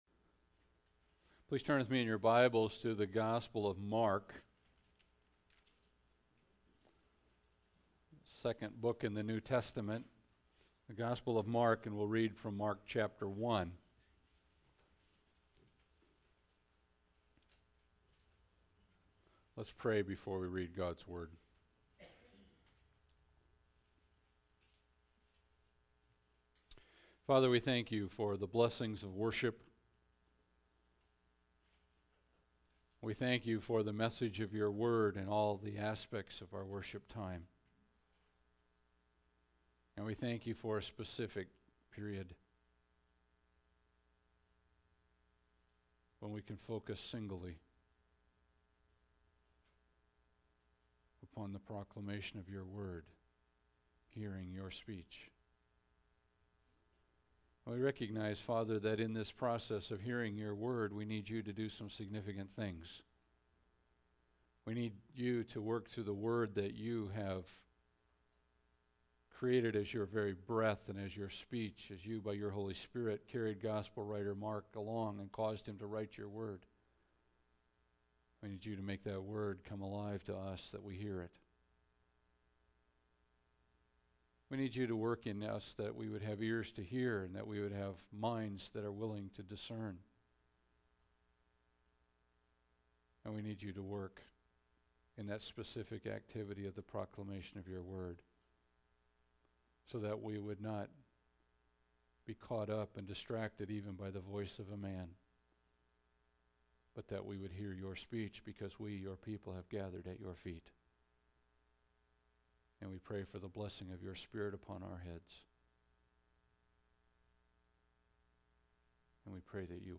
Mark 1:14-31 Service Type: Sunday Service Bible Text